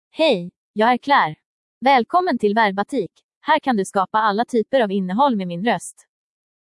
Claire — Female Swedish (Sweden) AI Voice | TTS, Voice Cloning & Video | Verbatik AI
Claire is a female AI voice for Swedish (Sweden).
Voice sample
Female
Claire delivers clear pronunciation with authentic Sweden Swedish intonation, making your content sound professionally produced.